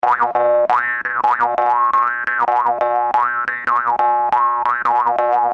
Его вибрирующие, гипнотические тона идеальны для медитации, релаксации и погружения в этническую атмосферу.
Звук варгана